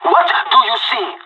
Game: Counter-Strike - Global Offensive SFX (PS3, Windows, Xbox 360) (gamerip) (2012)